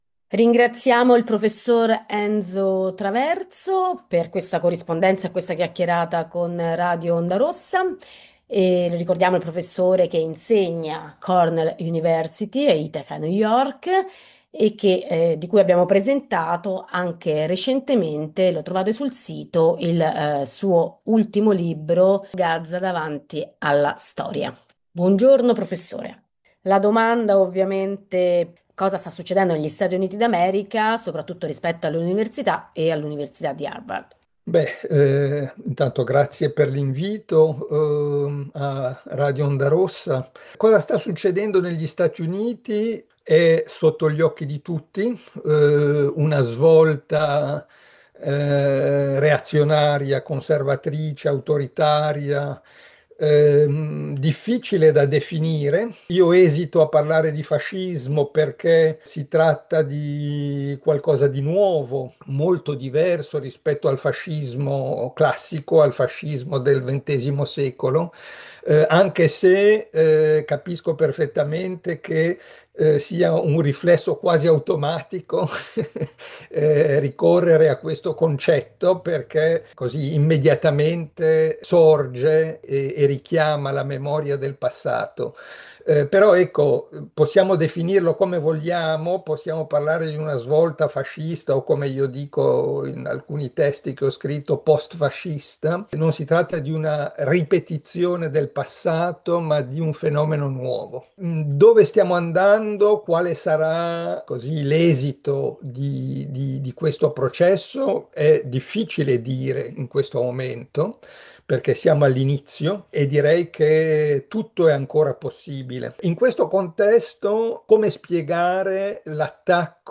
Ne parliamo con lo storico Enzo Traverso che insegna all'università di Ithaca, NY. Il taglio economico deciso da Trump nei confronti delle Università più importanti a cominciare da quella di Harvard è uno scontro interno alla stessa elite americana, essendo queste Università prestigiose che sfornano tutti i dirigenti dell'Amministrazione repubblicana e democratica.
traverso universita USA.ogg